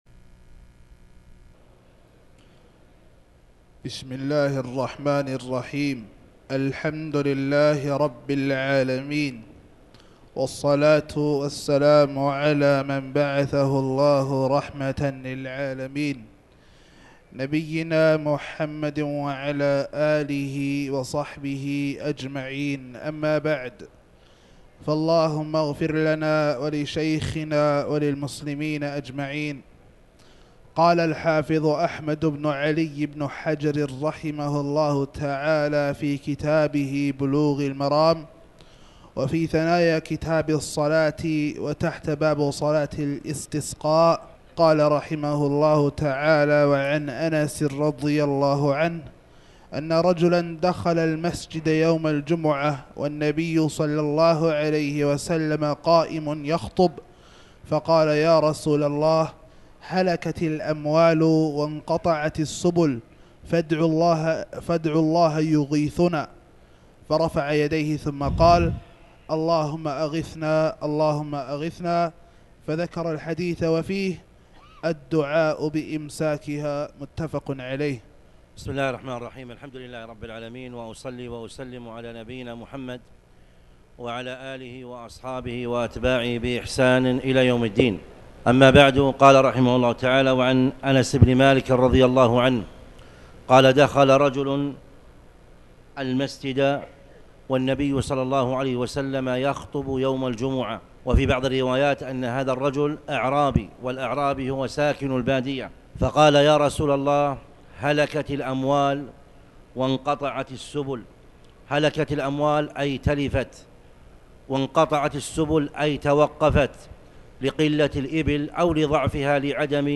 تاريخ النشر ١٧ ربيع الثاني ١٤٣٩ هـ المكان: المسجد الحرام الشيخ